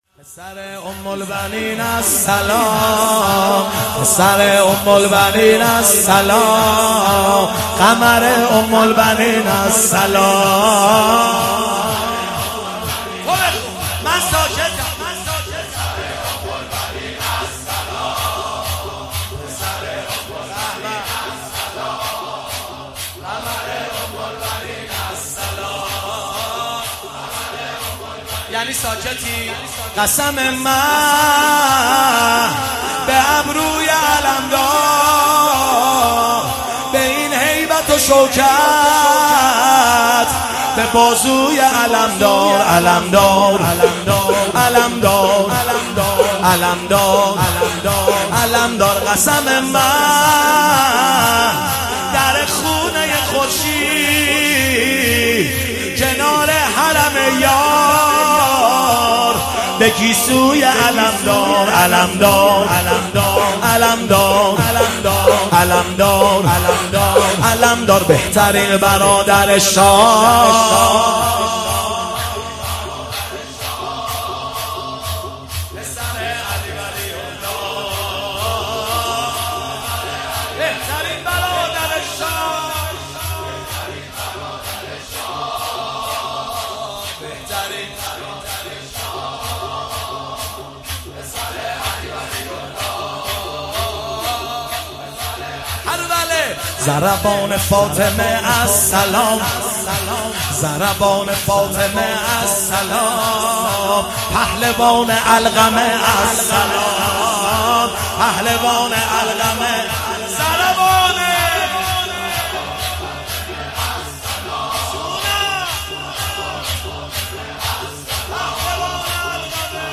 مداحی پسر ام البنین السلام
ایام فاطمیه ۹۷